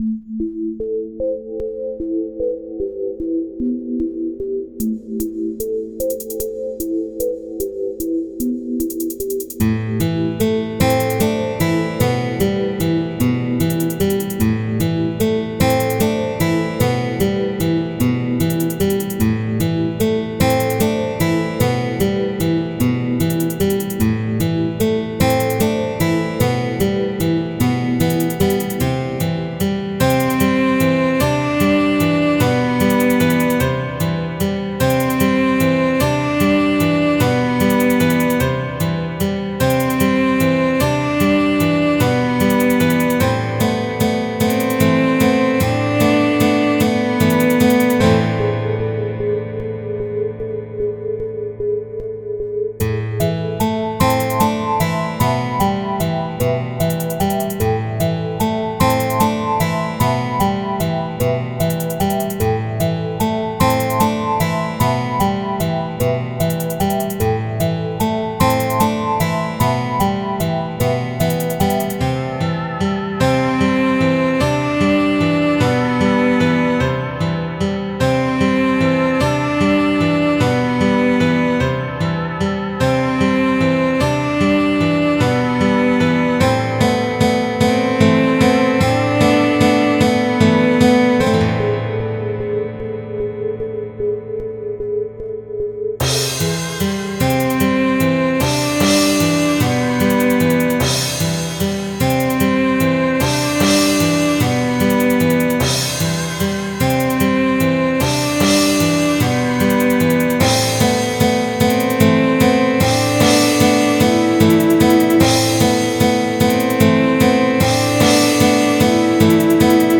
Electronic Experimental Indie DIY